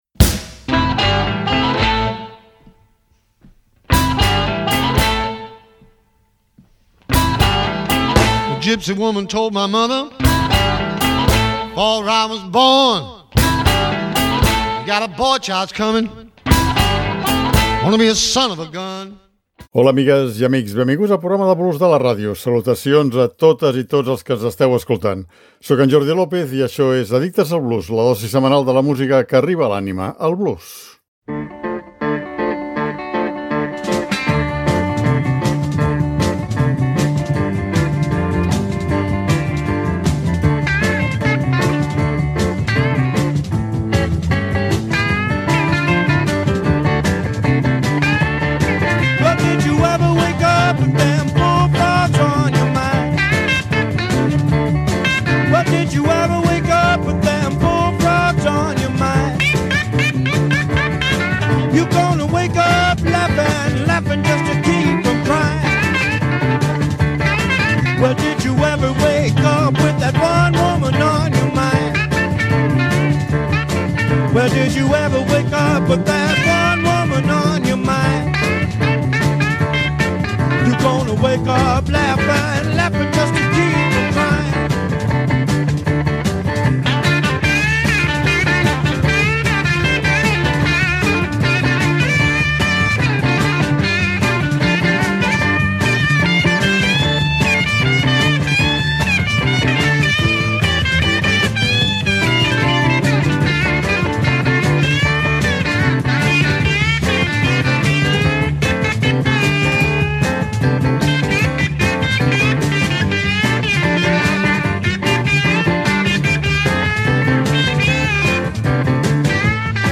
La banda era coneguda pel seu estil de blues barrejat amb funk psicodèlic.